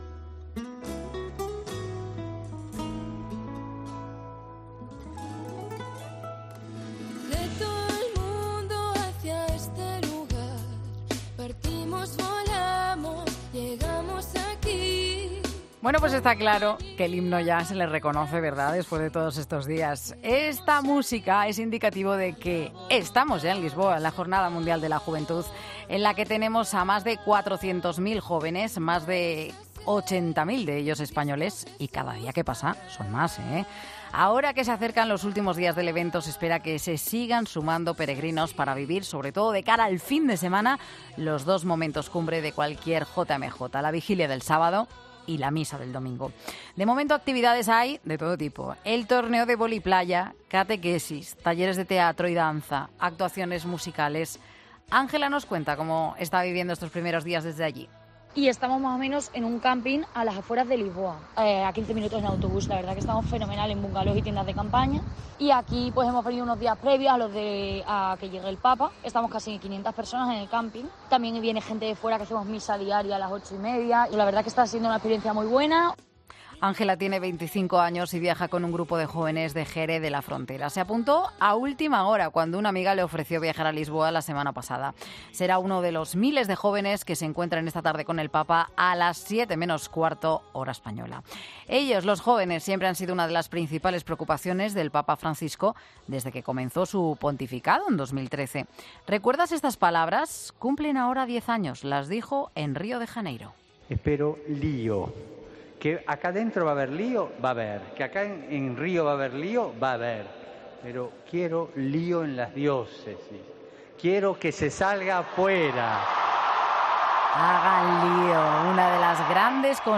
Un ejemplo de ello es la entrevista realizada el pasado 3 de agosto en 'Mediodía COPE' a algunos de los psicólogos que se reunieron en la JMJ para poner el foco en los problemas mentales más recurrentes entre los jóvenes.